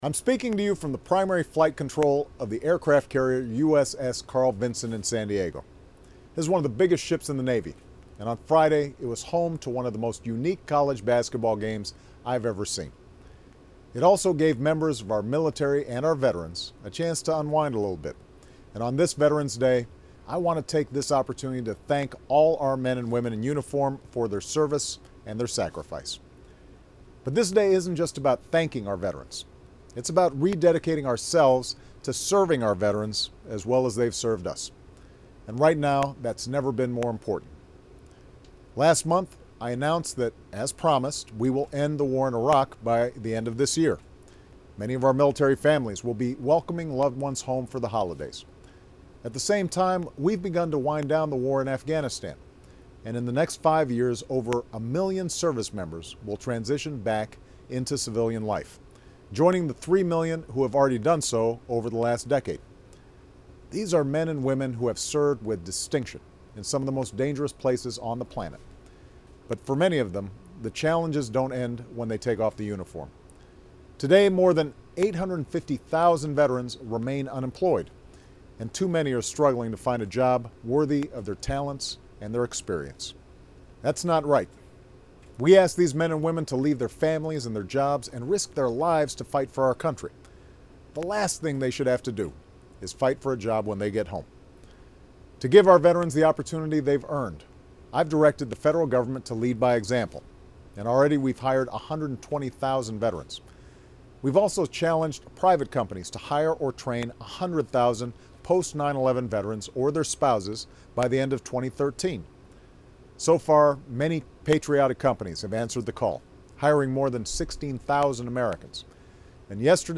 Remarks of President Barack Obama
San Diego, California